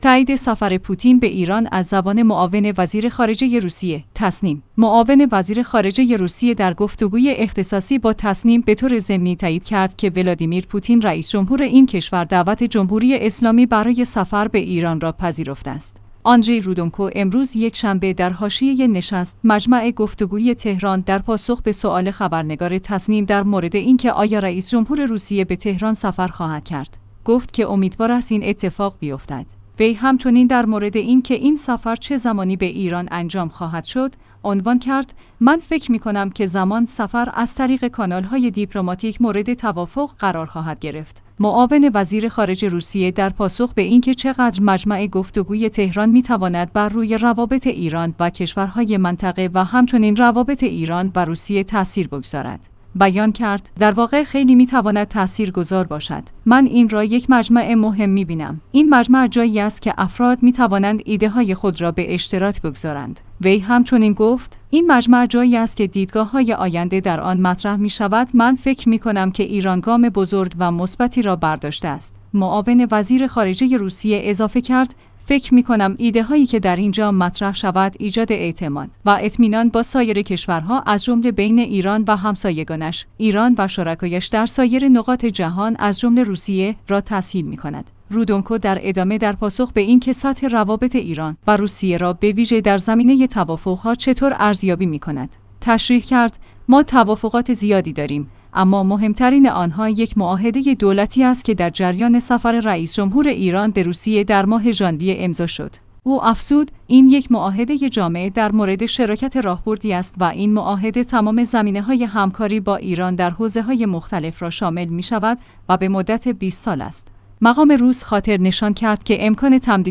تسنیم/ معاون وزیر خارجه روسیه در گفتگوی اختصاصی با تسنیم به طور ضمنی تایید کرد که ولادیمیر پوتین رئیس جمهور این کشور دعوت جمهوری اسلامی برای سفر به ایران را پذیرفته است.